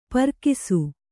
♪ parkisu